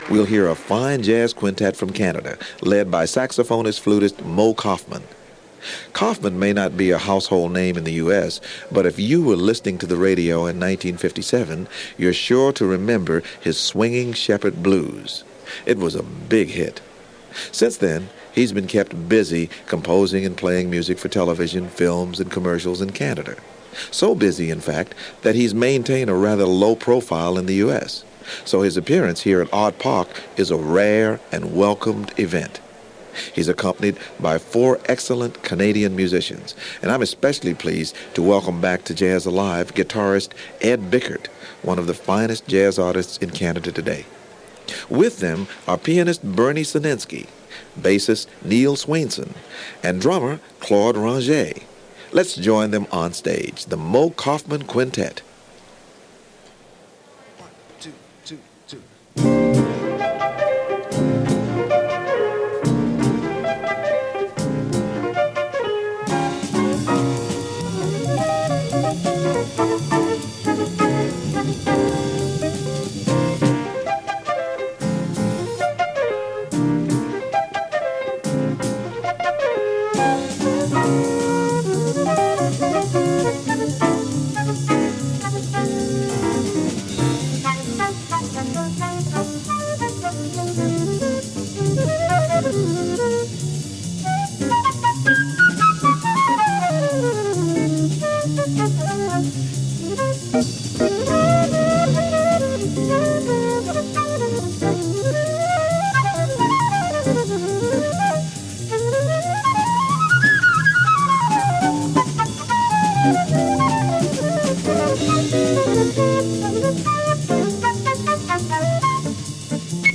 Swinging sounds from yonder North.